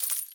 beyond/Assets/Sounds/Fantasy Interface Sounds/Coins 03.ogg at main
Coins 03.ogg